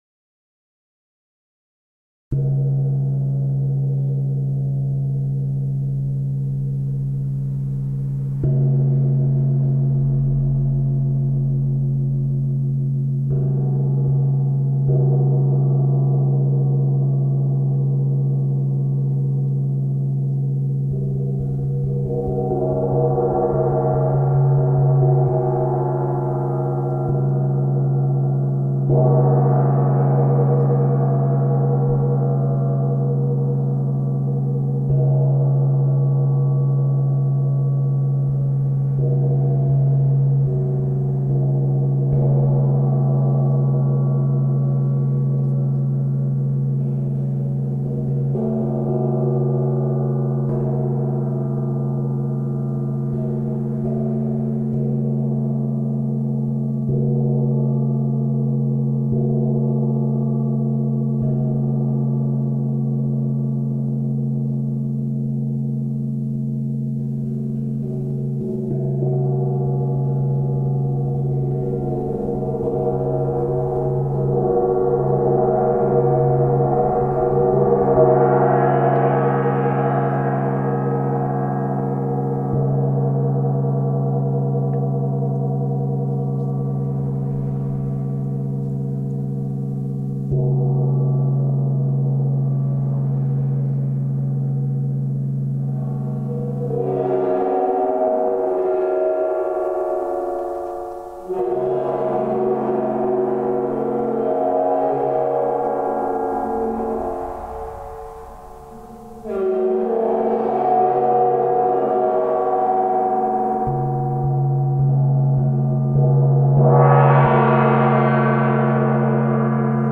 Traditional Chinese Instrument
Gong
Audio file of the Gong
Gong_audio_instrumento.mp3